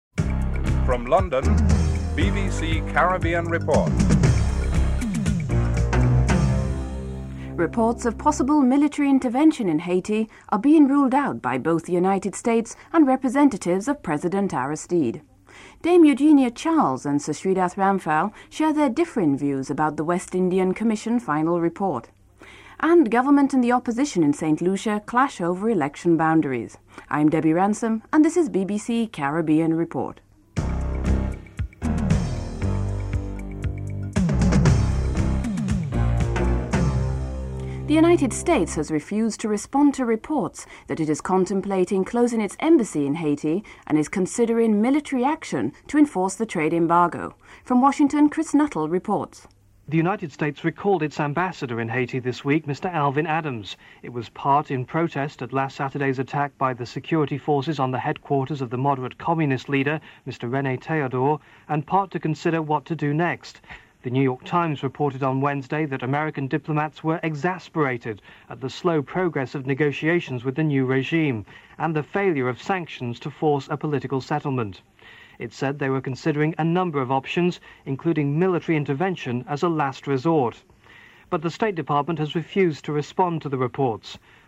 1. Headlines (00:00-00:36)
6. Interview with Haiti's Permanent representative to the United Nations, Fritz Longchamp on his views of possible military intervention in Haiti (02:55-06:12)